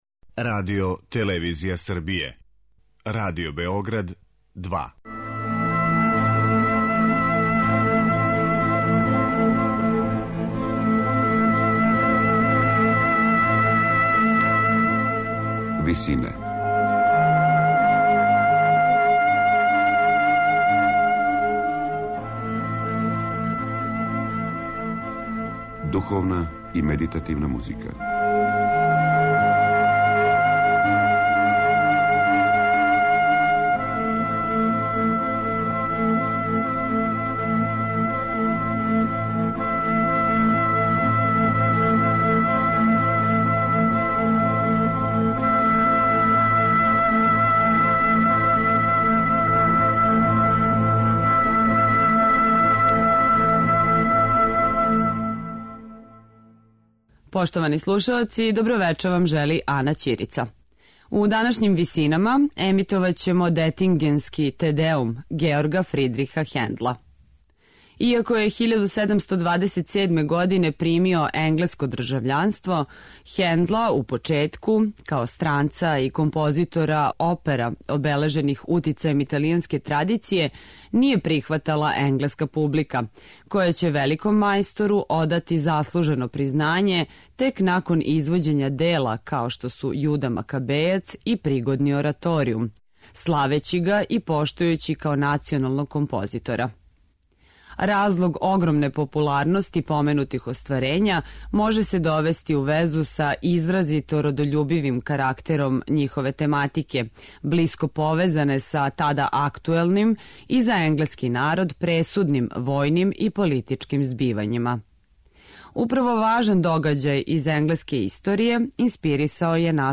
Величанствени и монументални карактер овог остварења из 1743. године, обележеног масивним и ефектним хорским звуком, открива близину чувеног ораторијума Месија, компонованог само годину дана раније.